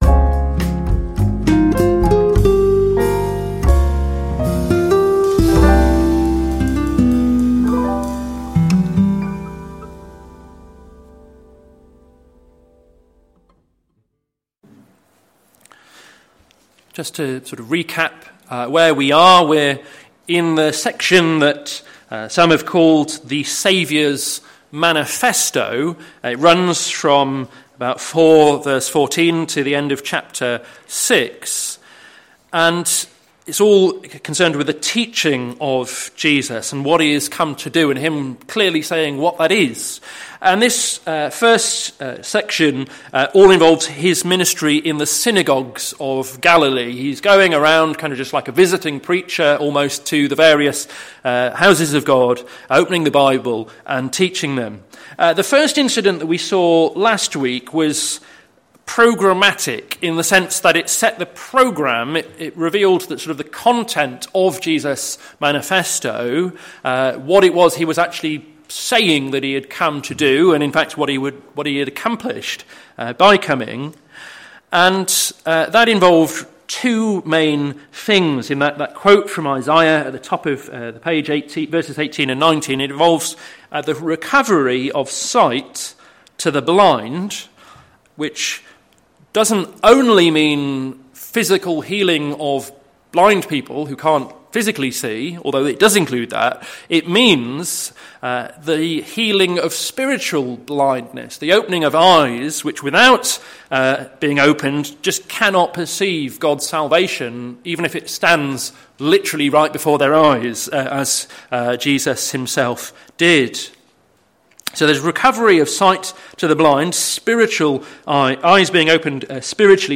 Sermon Series - To Seek and to Save the Lost - plfc (Pound Lane Free Church, Isleham, Cambridgeshire)